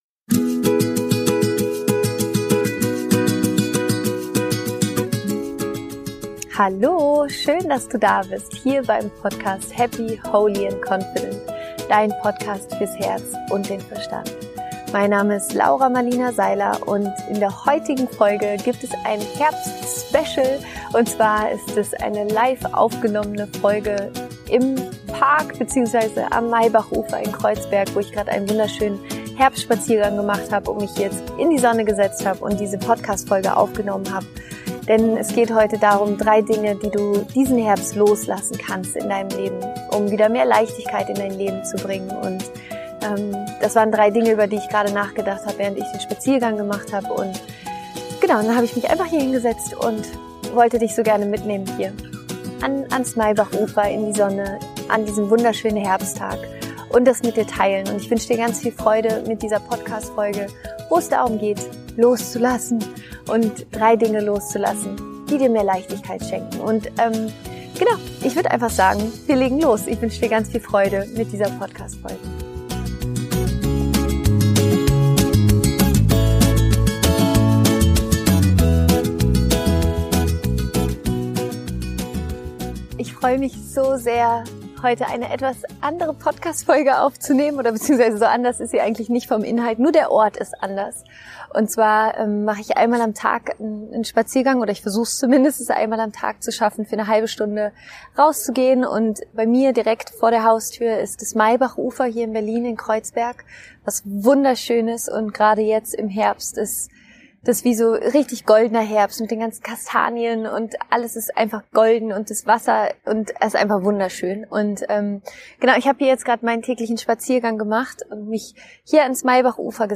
Ich nehme dich für diese Podcastfolge mit auf einen wunderschönen Herbstspaziergang durch Kreuzberg und spreche darüber was wir von der Natur und den Herbst übers Loslassen lernen können.
Ich wünsche dir viel Freude mit mir beim Herbstspaziergang.